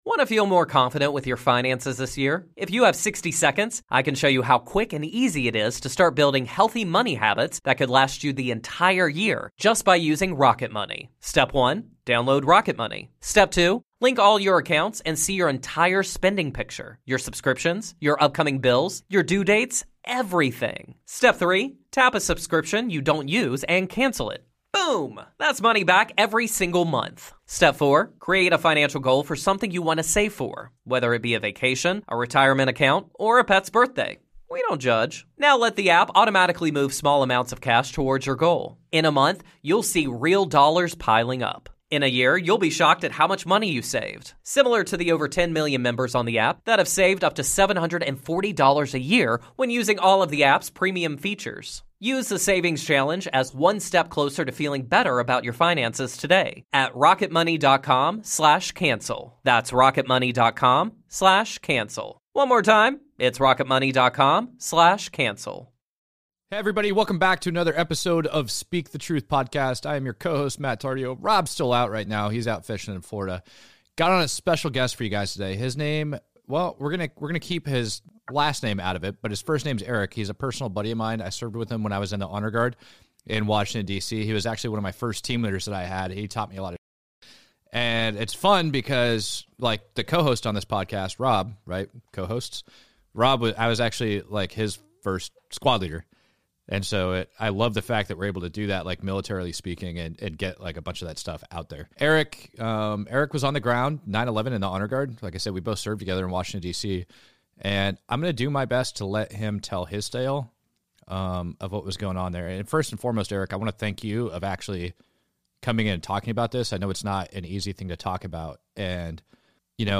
What Really Happened During 9/11 - Exclusive Interview
We bring on a gentleman who gives us exclusive interview about what really went on.